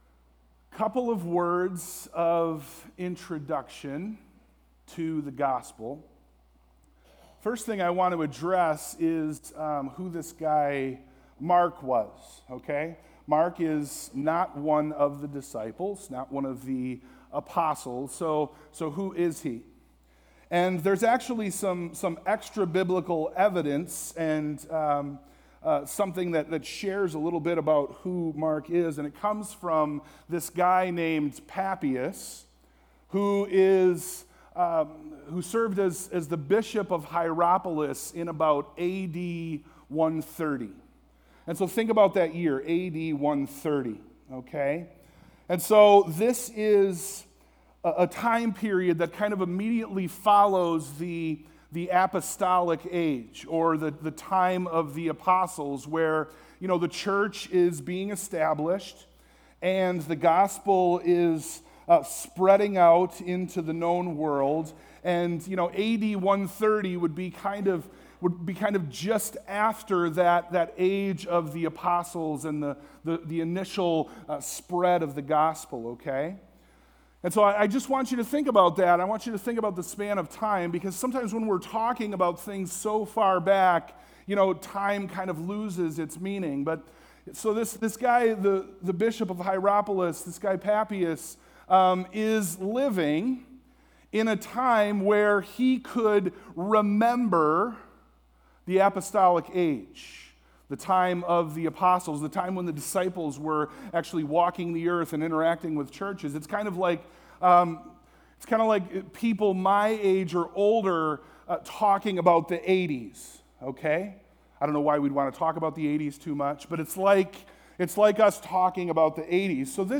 Service Type: AM
Sermon+Audio+-+Prepare+the+Way.mp3